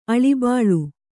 ♪ aḷibāḷu